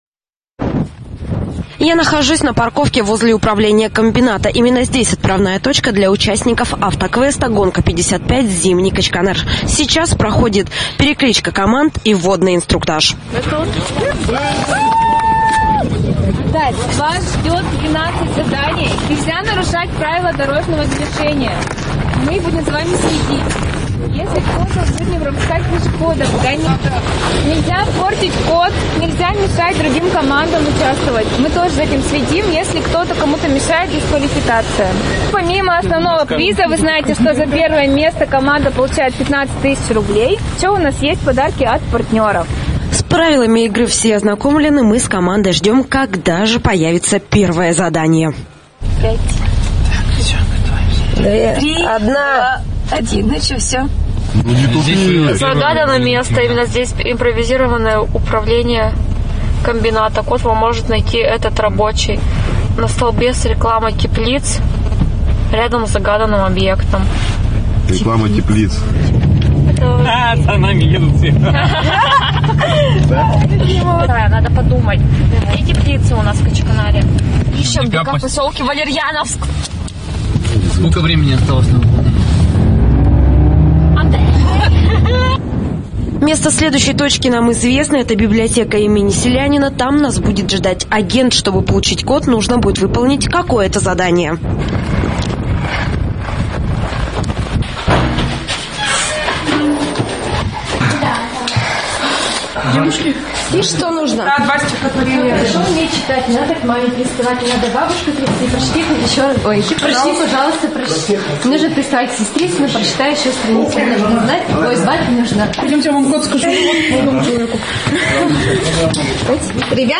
Аудиорепортаж: